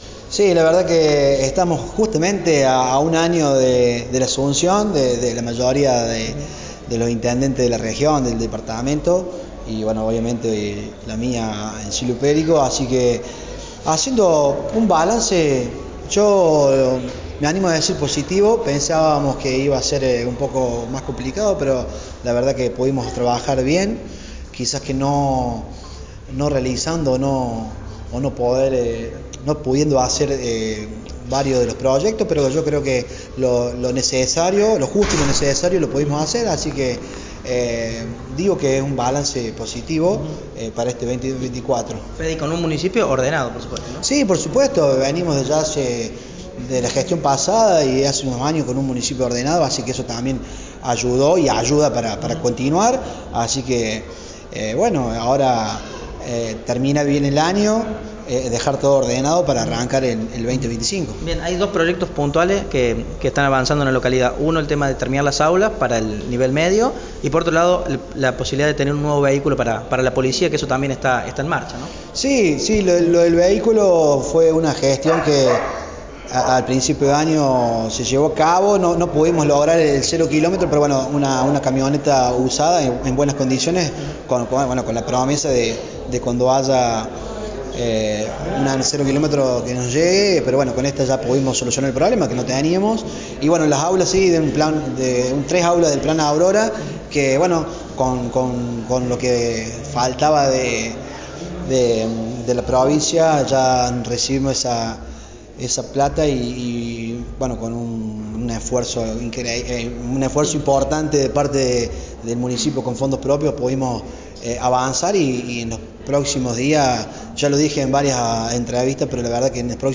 Federico Quiñonez cumple un año desde que asumió la intendencia de Silvio Pellico e hizo una evaluación de este tiempo de gestión, y de lo que se viene mirando al 2025.
entrevista-SILVIO-PELLICO.mp3